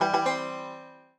banjo_egegc1.ogg